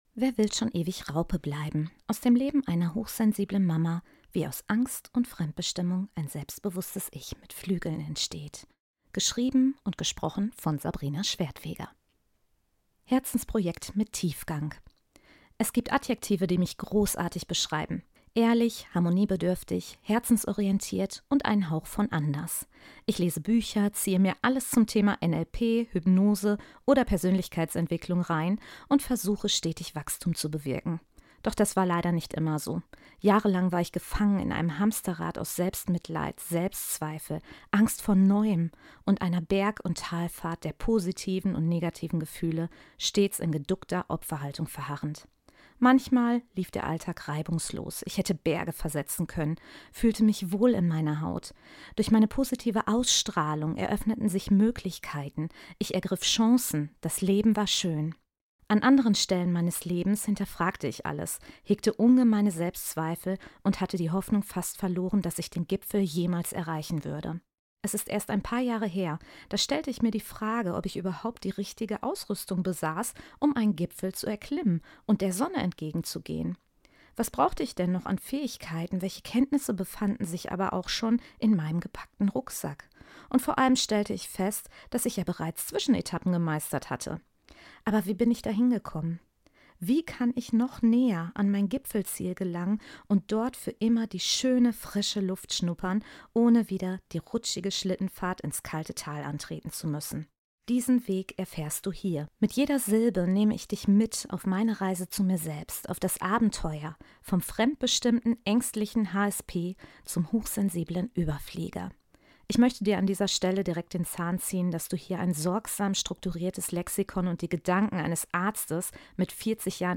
*Bonus - Hörbuch - Zum Einstimmen ~ Gelb & Glücklich - Mehr Sonne im Alltag Podcast